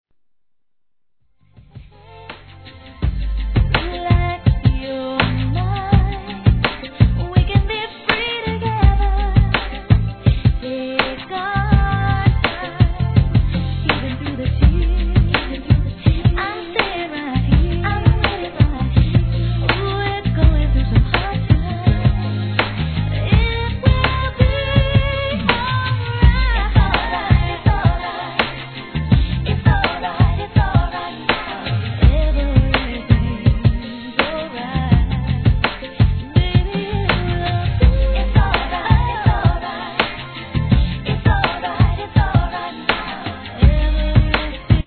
HIP HOP/R&B
ぐぐっと気持ち昂るサビに心躍り、時めいた方も多いのでは？